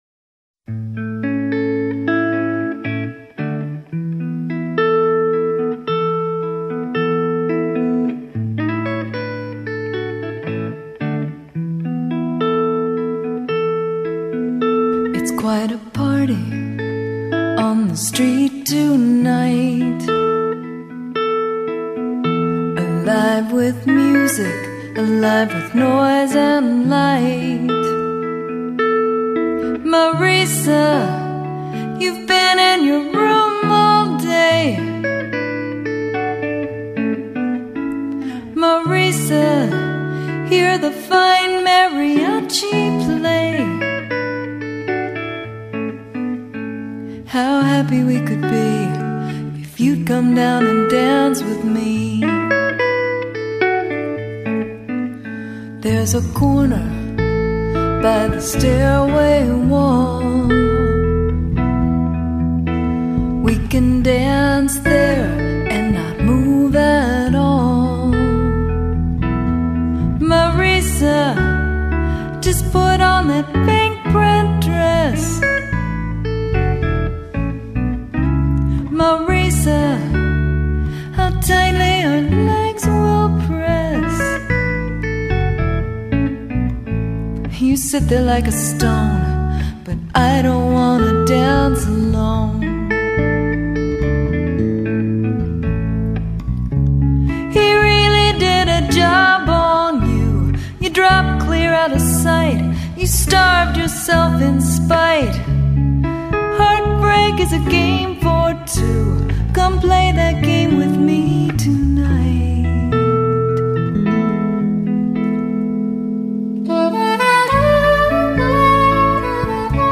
音樂類型：爵士樂
唱歌的人輕輕柔柔唱出在子夜裡的獨白，鋼琴前奏更舒展了女人晨起前的內心戲…